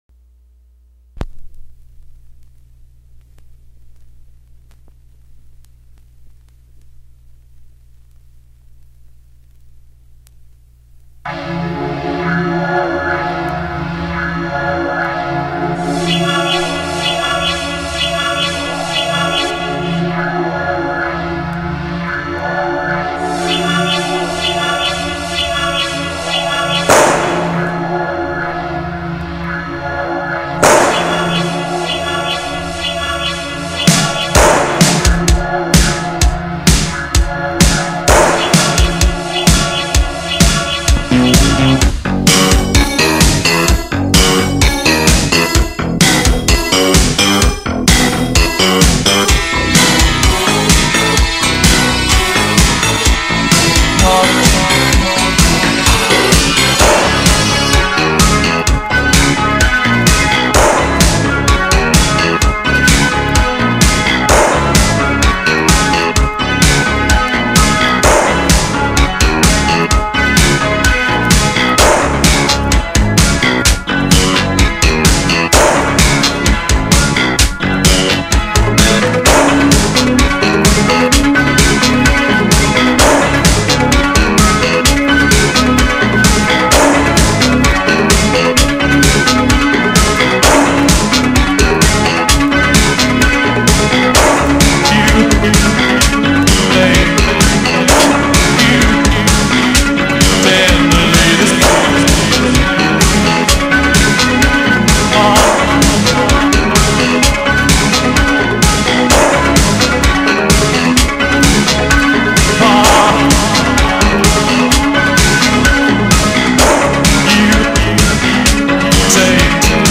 (translated from vinyl)